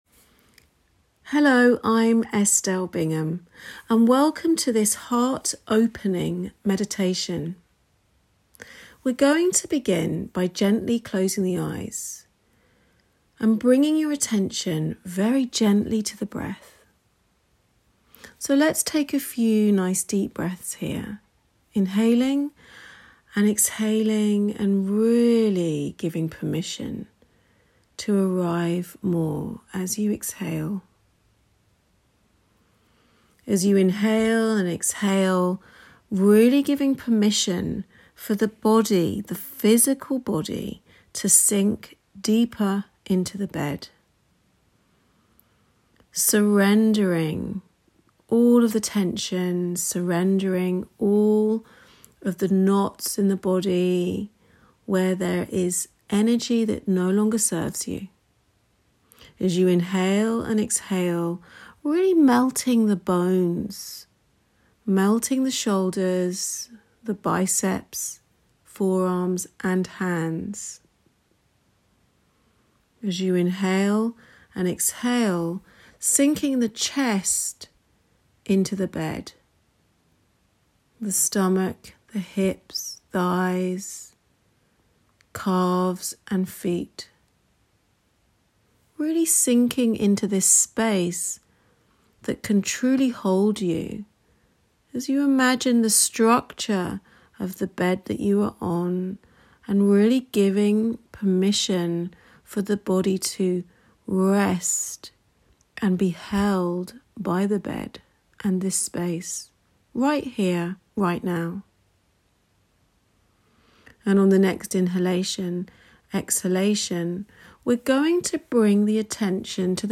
Dosha Balancing Sound Therapy Sessions